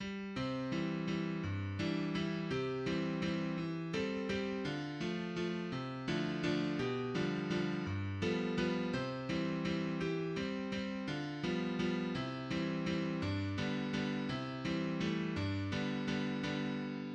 ジャンル ウィンナ・ワルツ
Moderato → Allegretto → Tempo di valse
4分の4拍子。装飾音を生かした軽妙な出だしに続き、タムタムの響き、ハープのカデンツァを経て、4分の3拍子となり、第2ワルツBのメロディが登場、その後、力強く締めくくり導入部を終わる。
第1ワルツAではハープを伴奏に弦楽器で流麗なメロディが奏される。第1ワルツBは生き生きとしている。